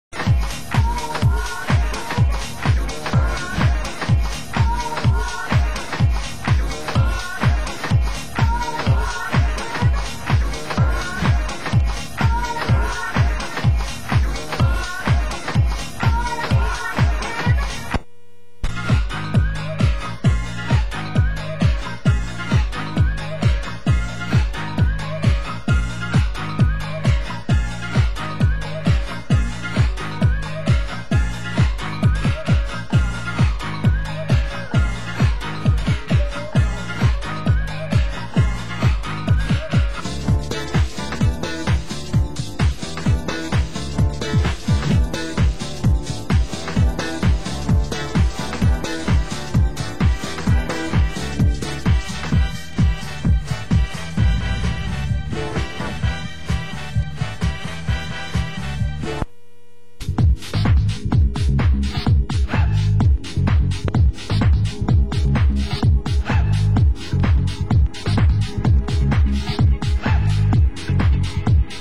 Genre: French House